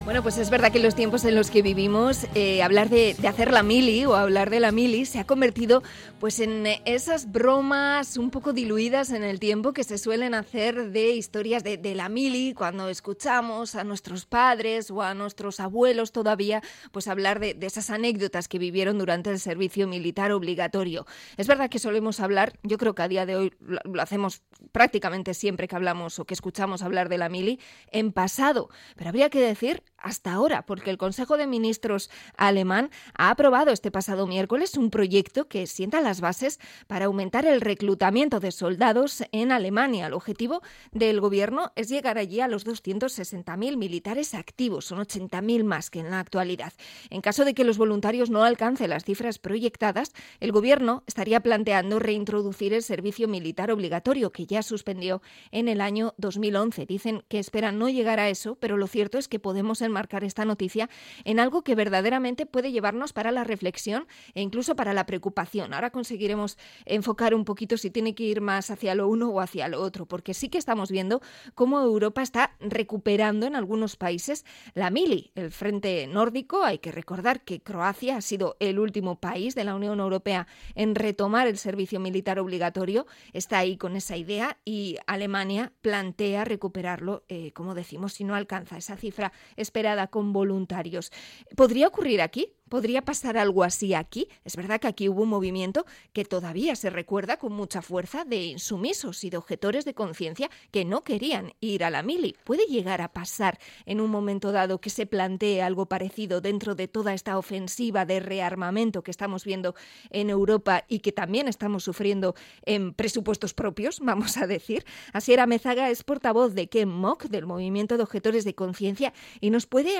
Entrevista al movimiento antimilitarista con motivo de la vuelta del servicio militar a Alemania